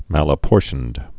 (mălə-pôrshənd)